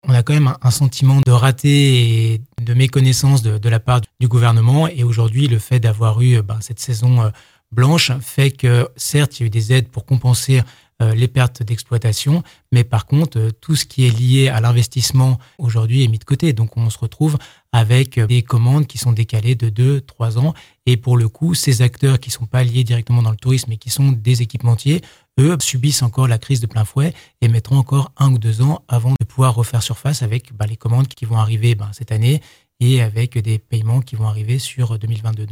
Cyril Pellevat, Sénateur de Haute-Savoie : Télécharger le podcast Partager :